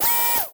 Enemy SFX
added servo sound